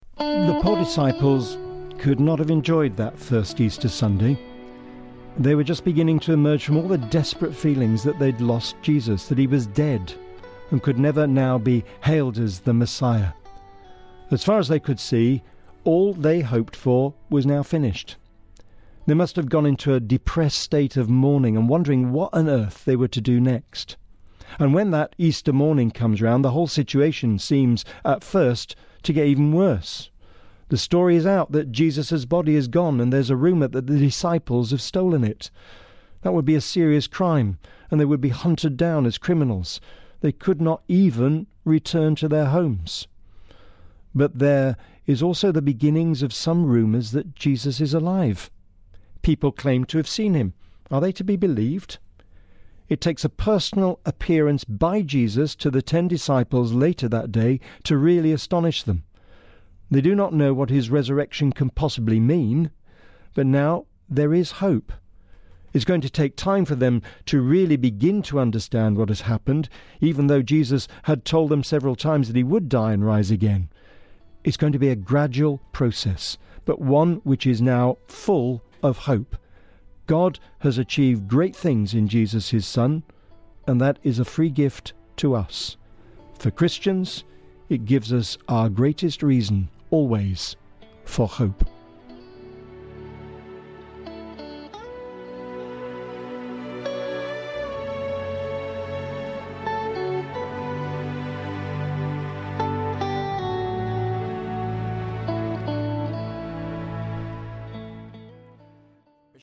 The Bishop of Salford, The Right Reverend John Arnold, gives his Easter message for 2015.